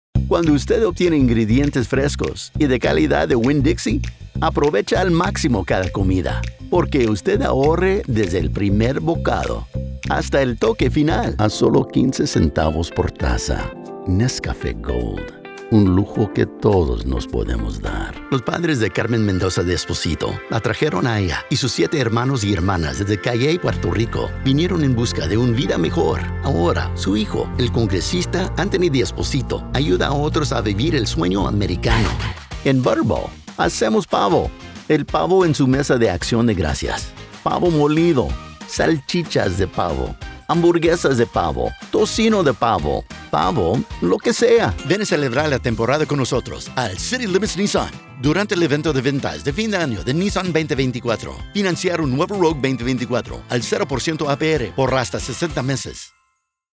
Full-Time, award-winning, bilingual voice actor with a pro studio.
Commercial Demo - Spanish
Southern, Hispanic, Mexican, Latino, American Standard English
Middle Aged